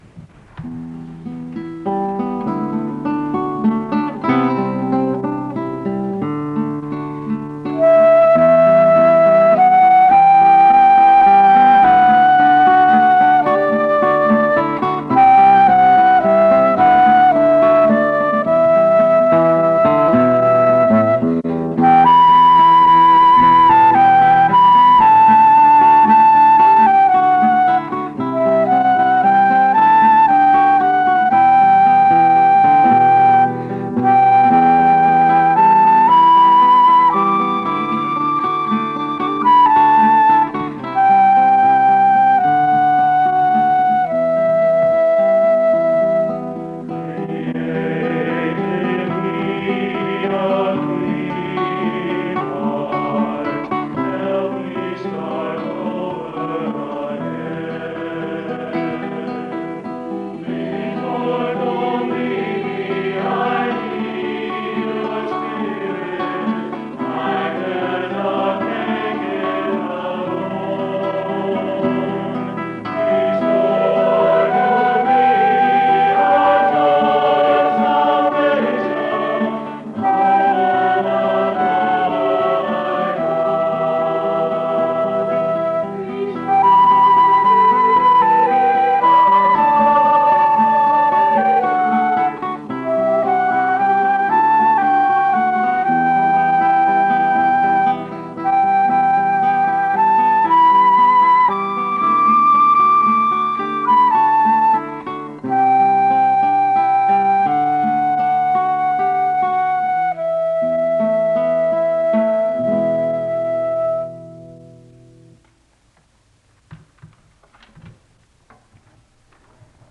SEBTS Chapel
The choir sings a song of worship (31:05-33:54).